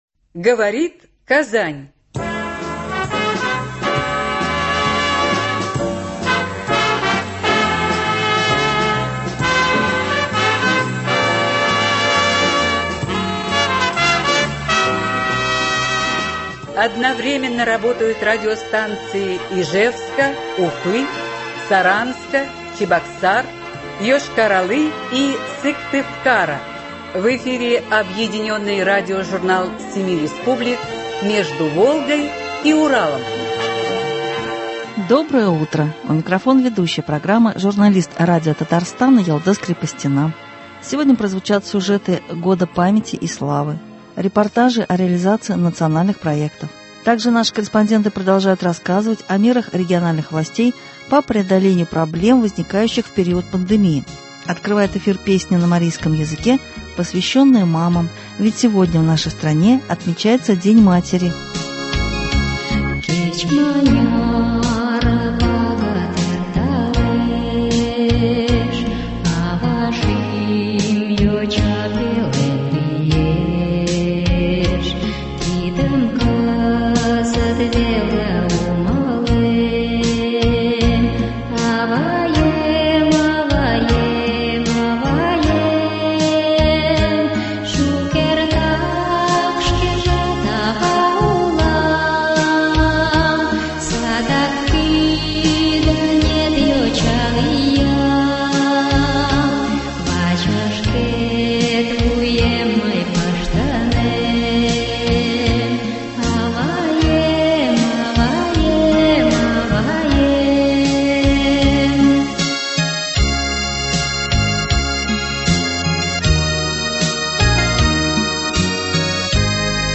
Объединенный радиожурнал семи республик.
Сегодня прозвучат сюжеты Года памяти и славы, репортажи о реализации Национальных проектов. Также наши корреспонденты продолжают рассказывать о мерах региональных властей по преодолению проблем, возникающих в период пандемии. Открывает эфир песня на марийском языке, посвящённая мамам — сегодня в нашей стране отмечается День матери.